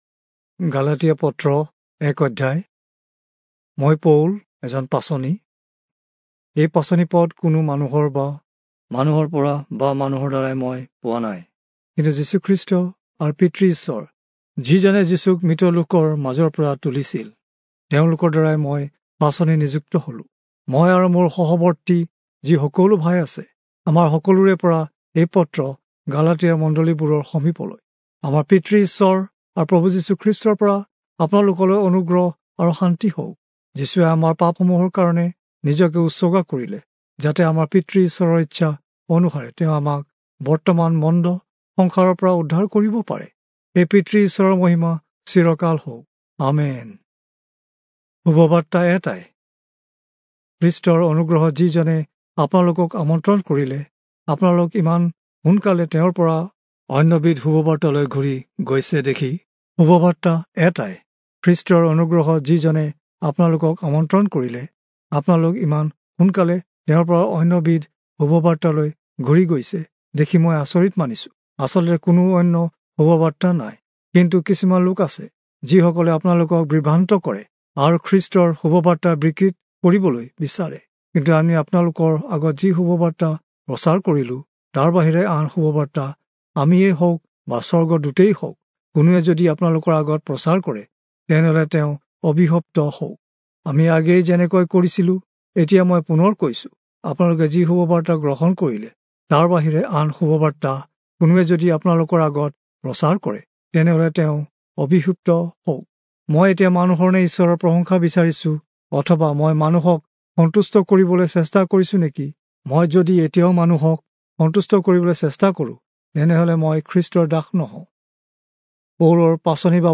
Assamese Audio Bible - Galatians 3 in Akjv bible version